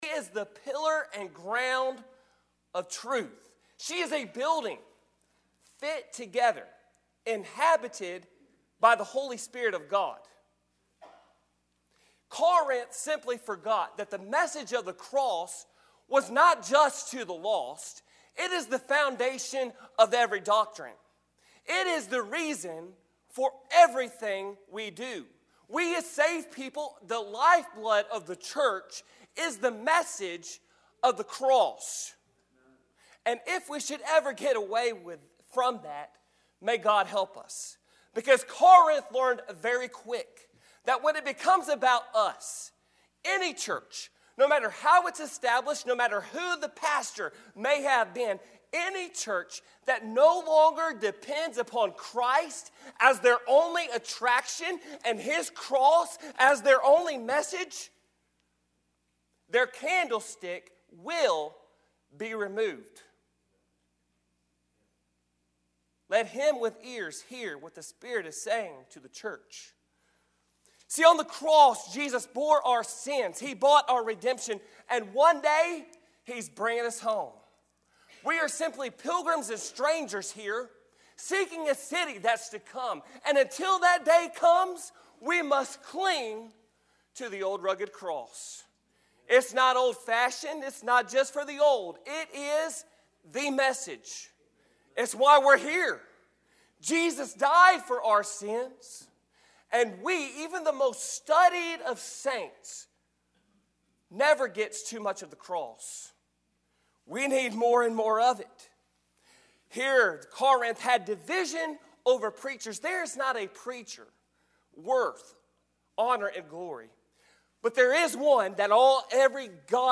Passage: 1 Corinthians 1:18-24 Service Type: Sunday Morning